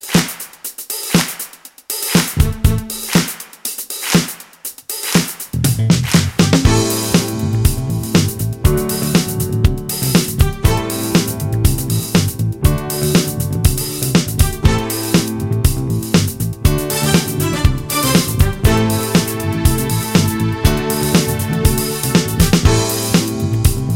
No Drums, Bass or Guitars Disco 3:36 Buy £1.50